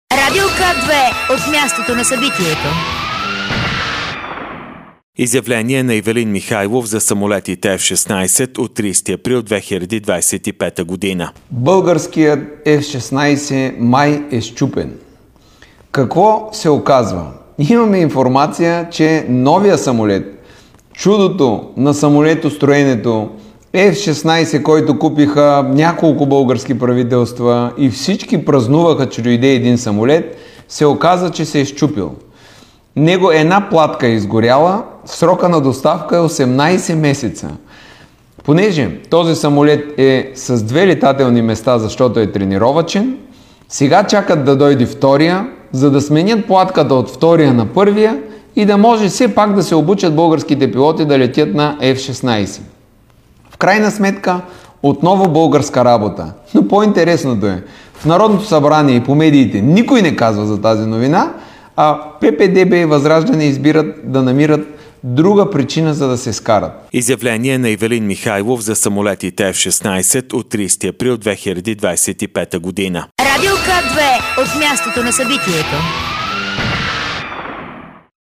Изявление на премиера Росен Желязков за системата БГ Алърт от 30.04.2025